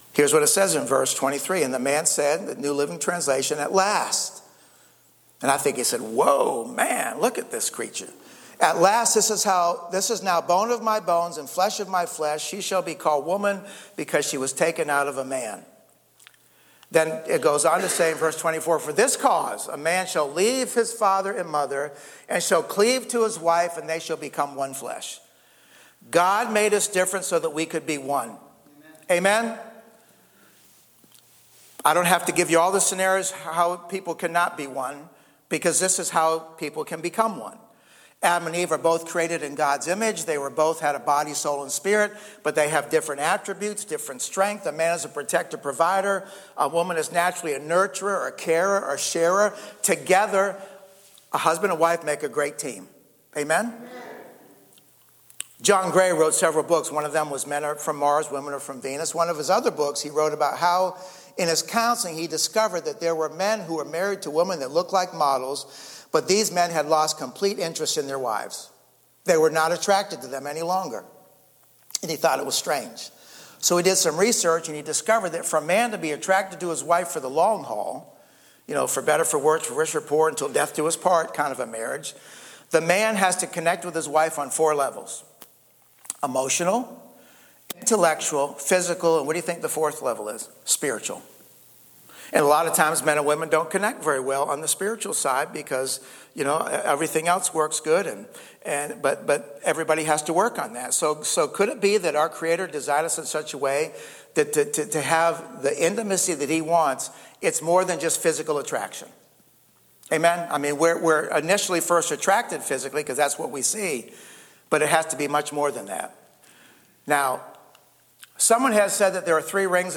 Note: Due to a technical error, the first part of this message was not recorded.